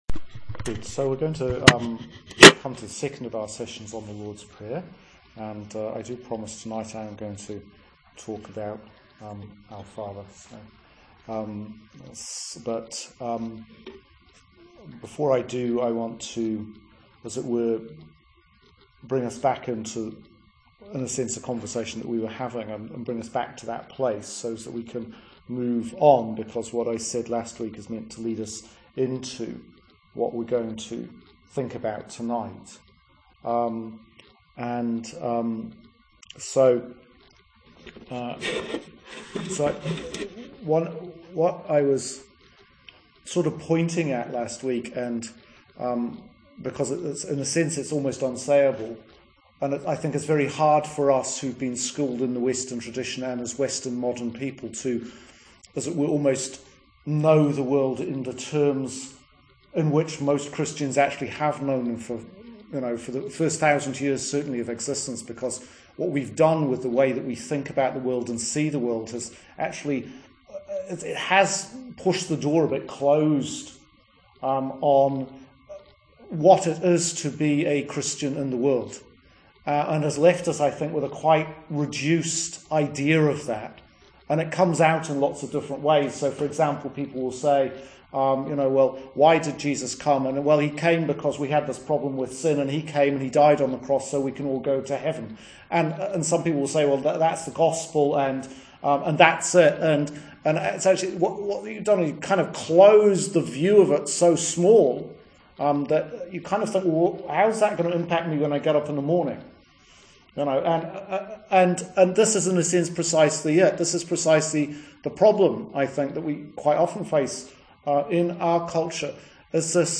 Sermon for 4th Sunday in Lent Year B 2018